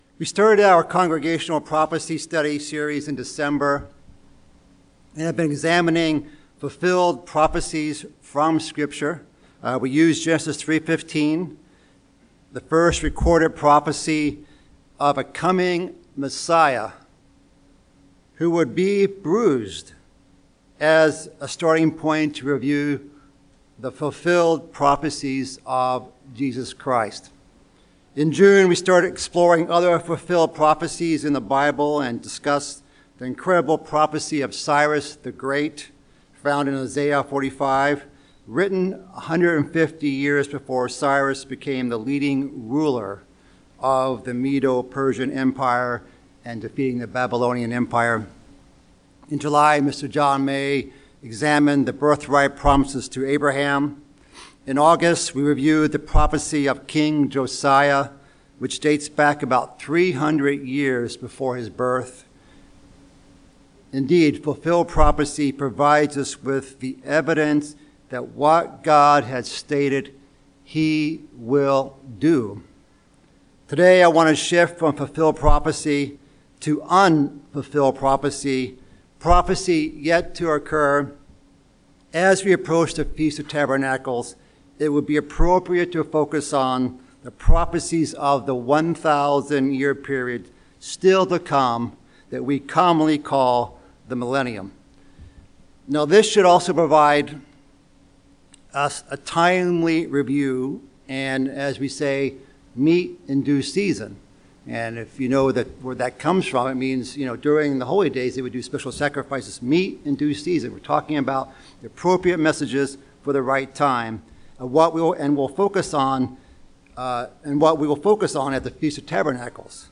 Given in Chicago, IL Beloit, WI Northwest Indiana